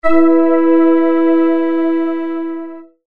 According to the virtual generation of the wave, there are no artifacts as known from classical wave table style generation, because wave transformation is operated continously.
Demo Sound produced with the triple ADSR function and 7 waves each.
wavetablemixsound.mp3